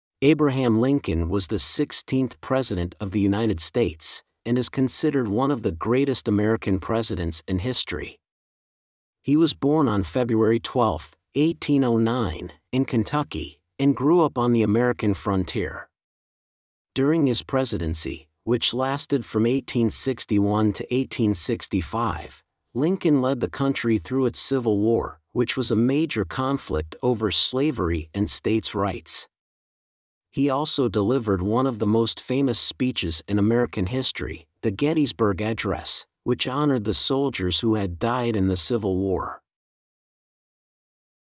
Two MP3s and transcript: A narration about the event and a factual Q&A segment.